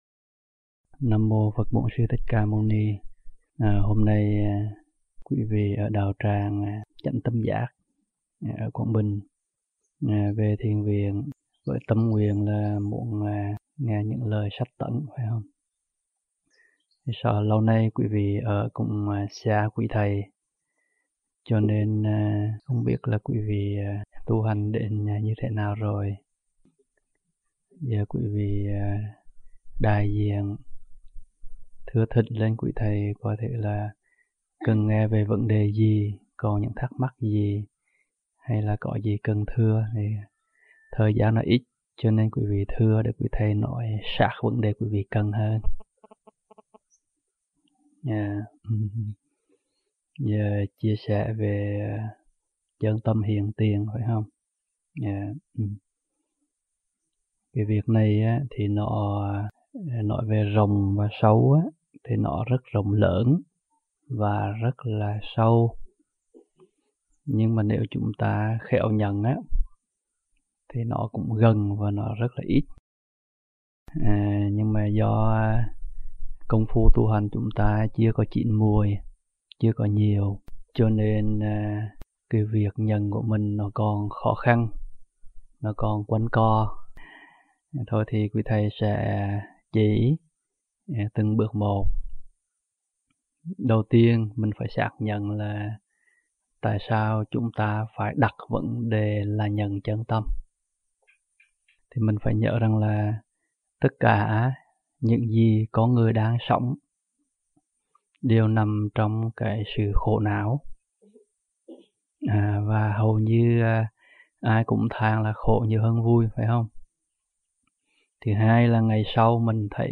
tại Thiền viện